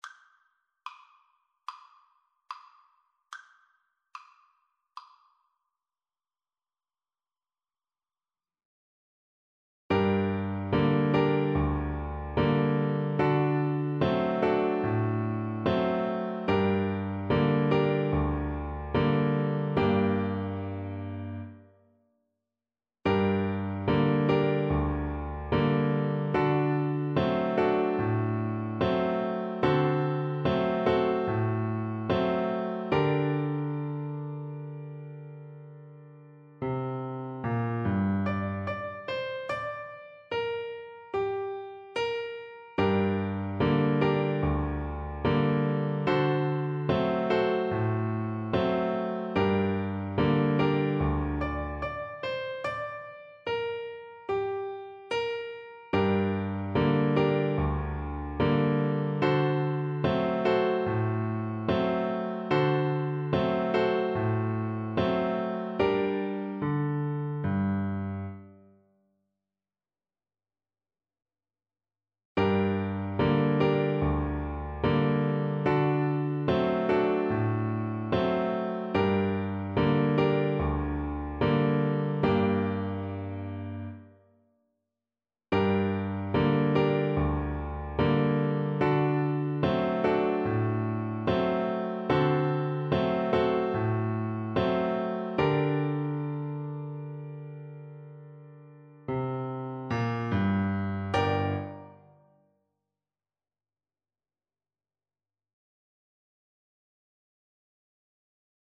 4/4 (View more 4/4 Music)
G5-D6
Decisively